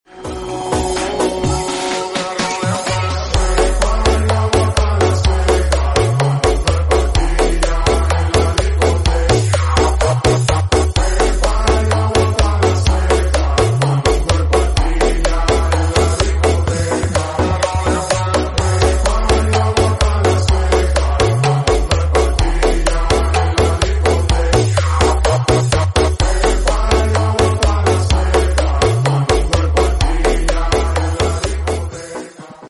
Karnaval Boyolangu Ngranti Gayeng Poll😁